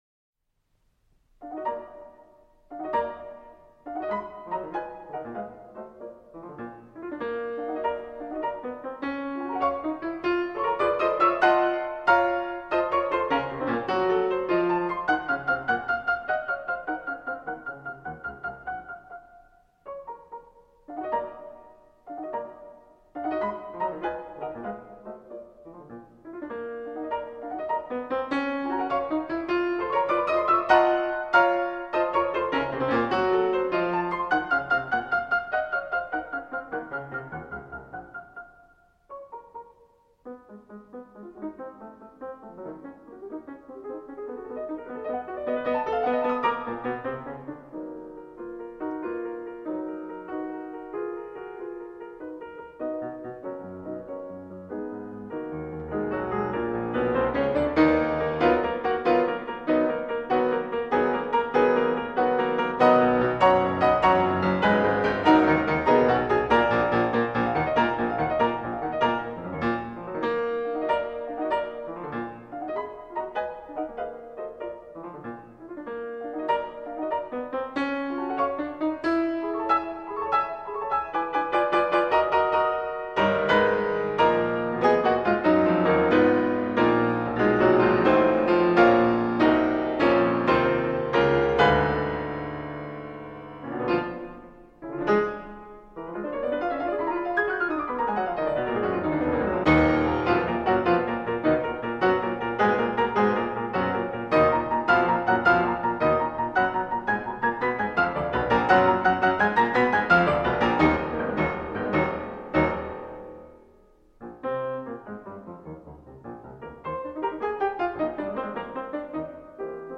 Scherzo
Usually a fast-moving humorous composition. 06 Brahms Scherzo in Eb minor Op 4